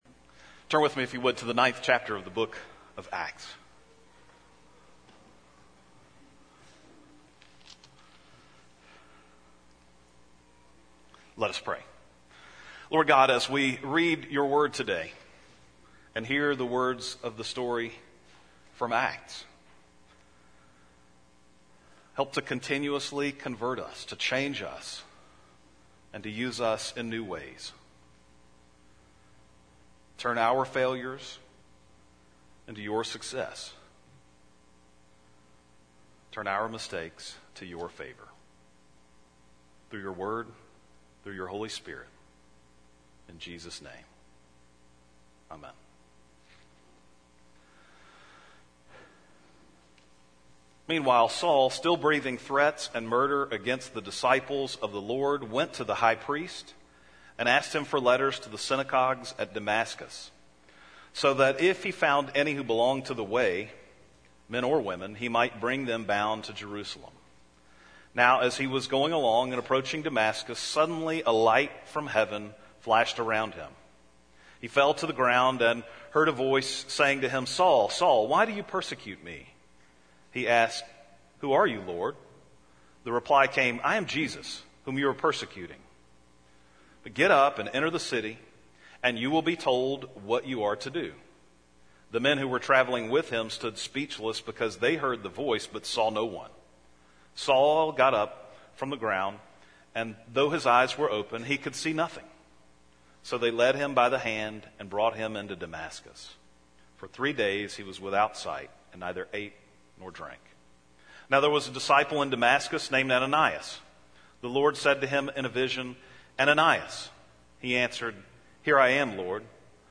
Acts 9:1-20 Service Type: Sunday Morning Bible Text